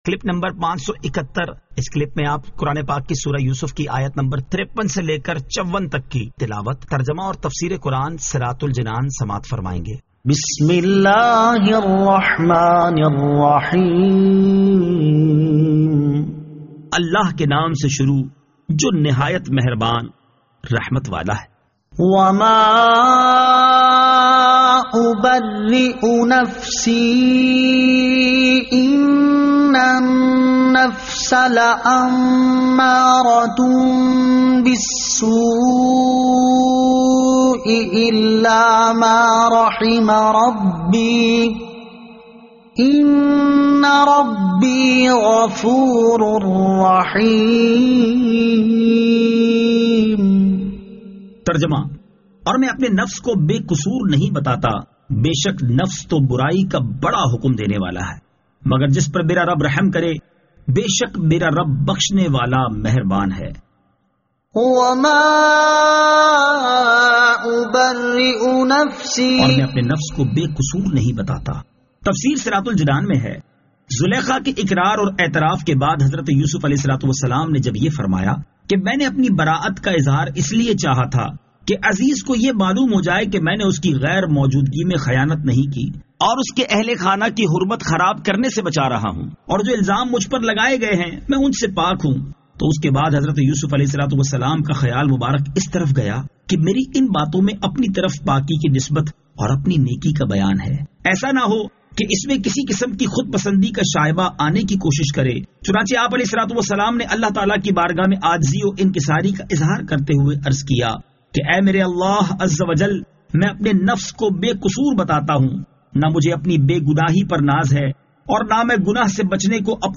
Surah Yusuf Ayat 53 To 54 Tilawat , Tarjama , Tafseer